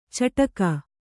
♪ caṭaka